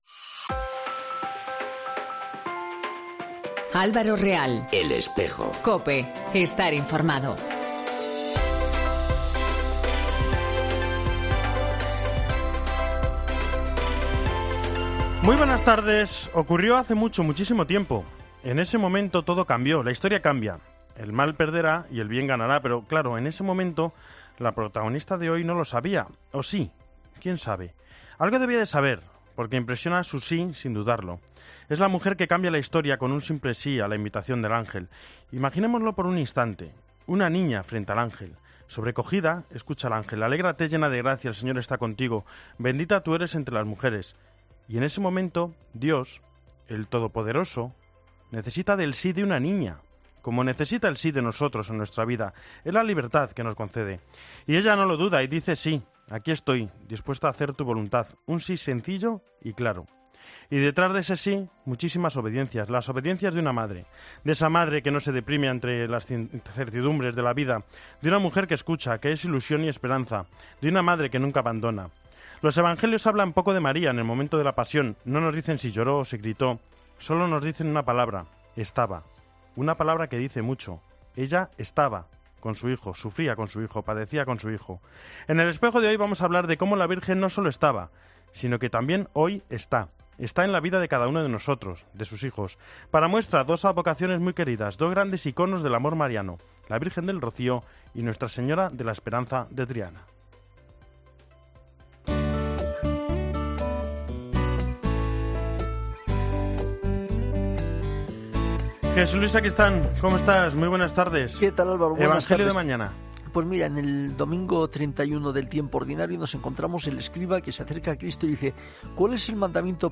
entrevista del Rocío y la Esperanza de Triana y firma de...